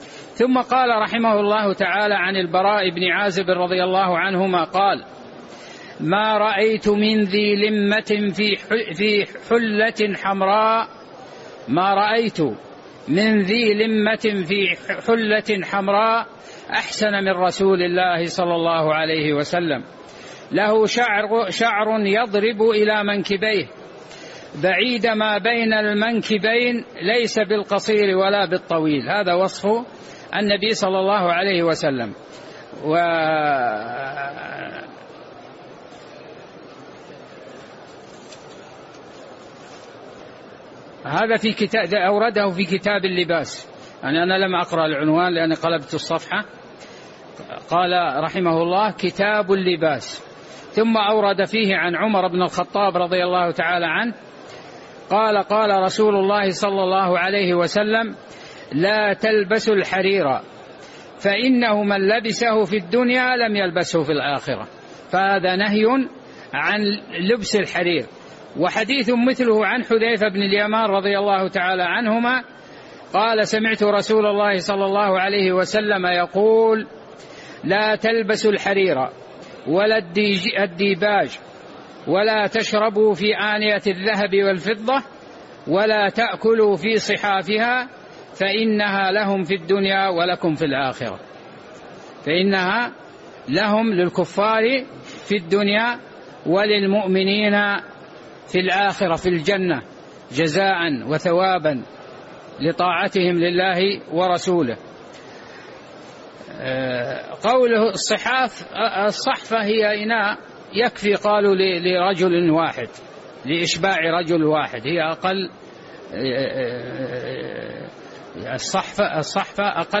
تاريخ النشر ٢٠ رجب ١٤٣٧ هـ المكان: المسجد النبوي الشيخ